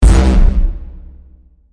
科幻武器 " 科幻步枪
描述：通过声音合成创建的声音效果。
Tag: 机枪 军事 士兵 射击 武器 子弹 作战 训练 射击 步枪 实弹射击 打死 科幻 爆炸 狙击战 手枪 FPS 攻击 武器 军队 技术 射击 杀死 射击 射击